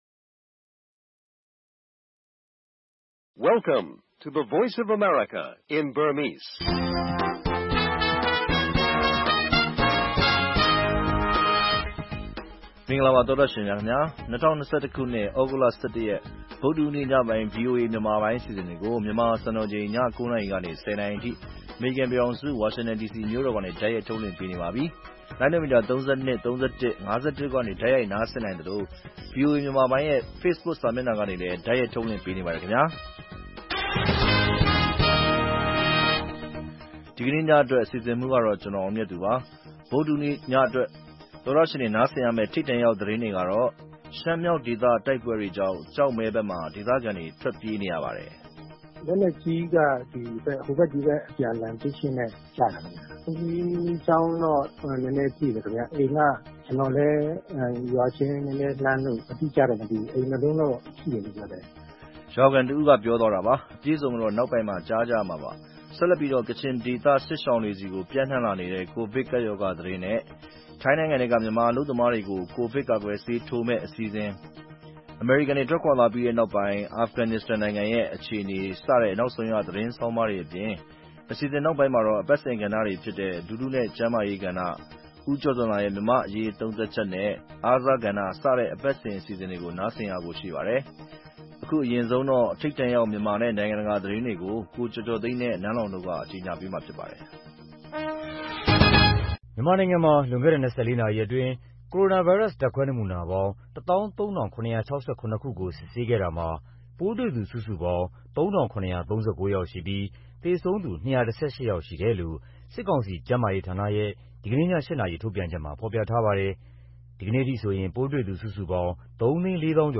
VOA ရေဒီယိုညပိုင်း ၉း၀၀-၁၀း၀၀ တိုက်ရိုက်ထုတ်လွှင့်မှု(သြဂုတ်၁၁၊၂၀၂၁)